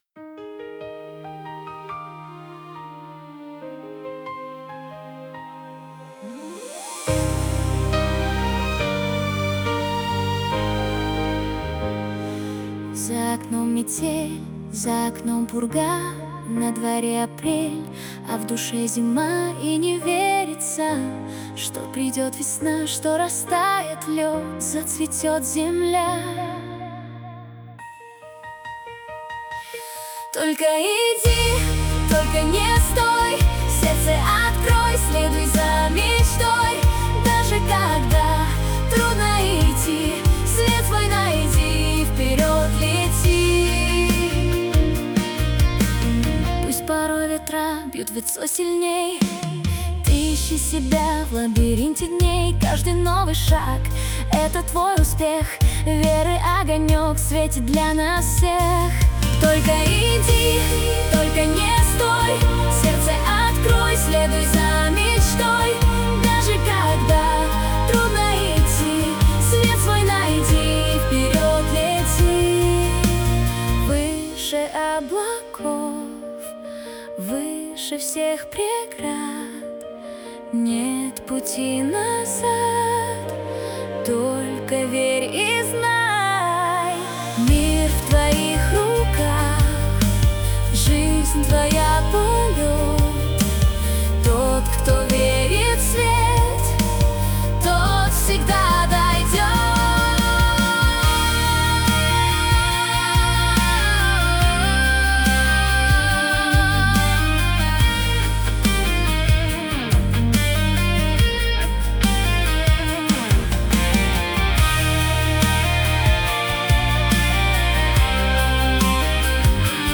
• Жанр: Детские песни
подростковые песни